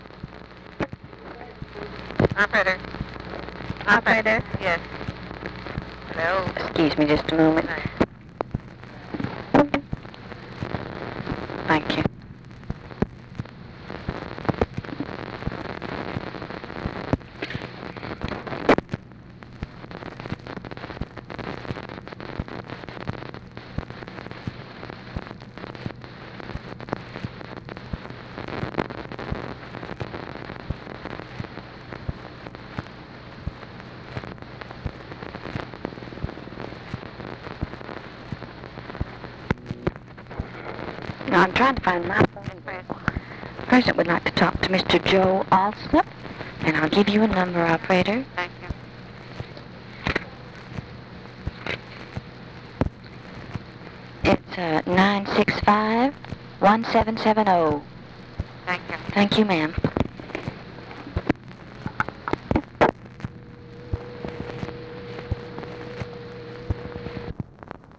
SECRETARY AND OPERATOR TRYING TO PLACE CALL TO JOSEPH ALSOP
Secret White House Tapes